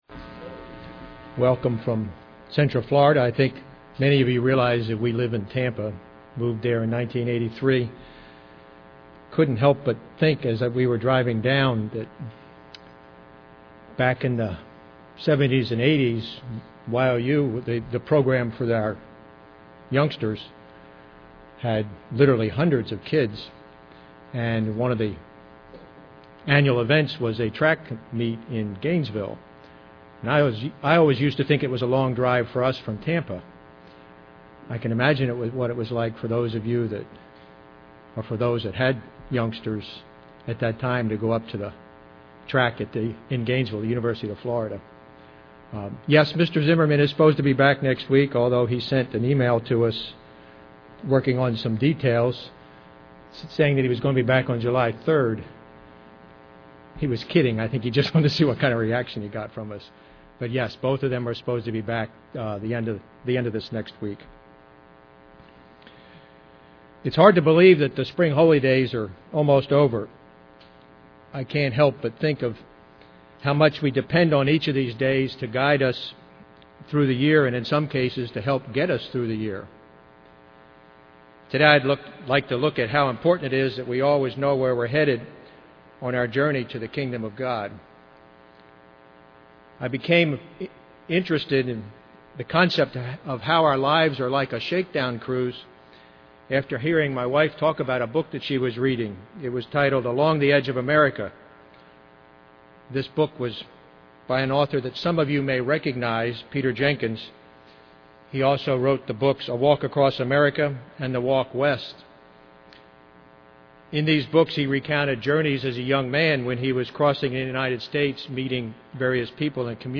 Given in Ft. Myers, FL
UCG Sermon Studying the bible?